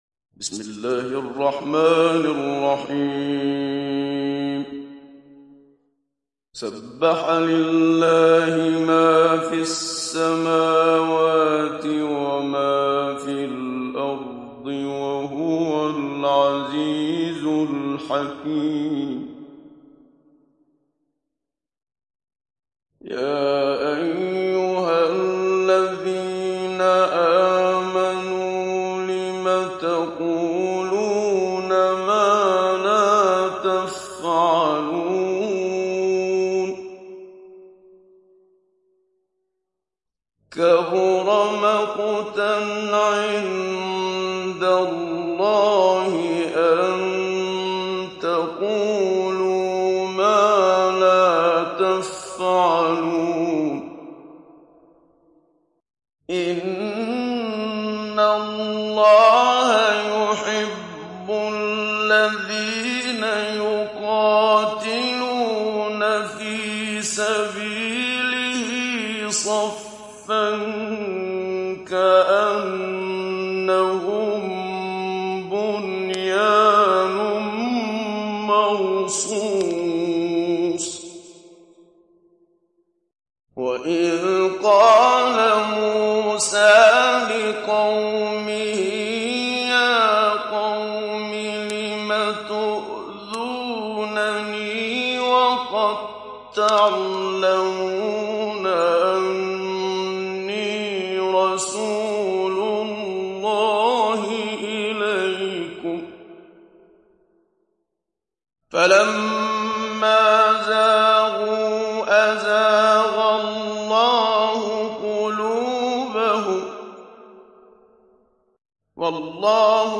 Sourate As Saf Télécharger mp3 Muhammad Siddiq Minshawi Mujawwad Riwayat Hafs an Assim, Téléchargez le Coran et écoutez les liens directs complets mp3
Télécharger Sourate As Saf Muhammad Siddiq Minshawi Mujawwad